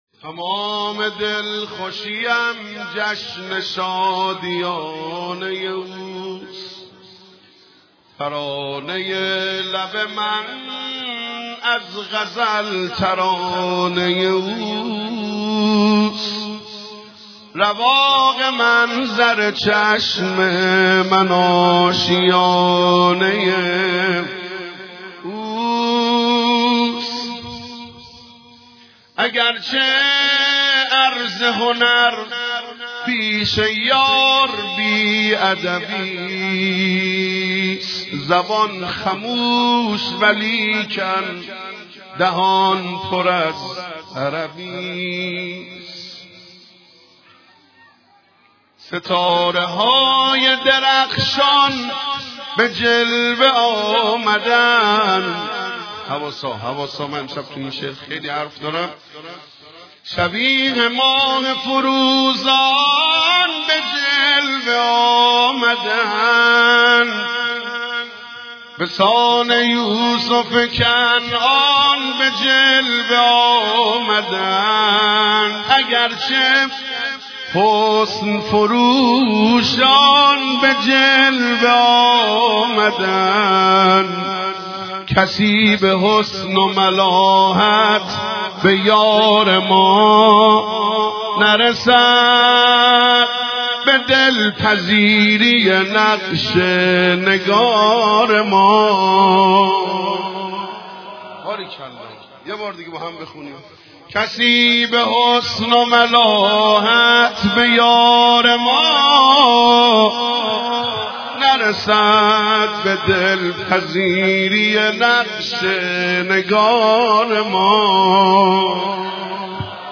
تمام دلخوشیم جشن شادیانه ی اوست (مدح) ولادت امام زمان (عجل الله فرجه)